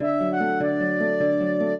flute-harp
minuet11-6.wav